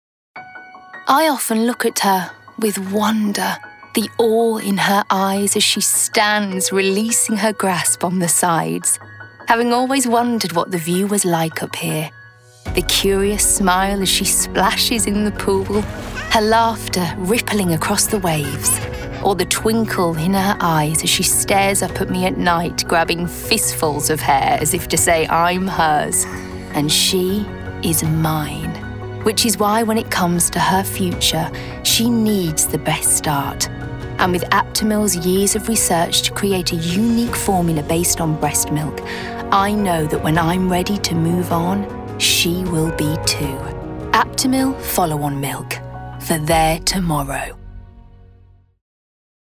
Voice Reel
Aptamil - Soothing, Warm, Nurturing
Aptamil - Soothing, Warm, Nurturing.mp3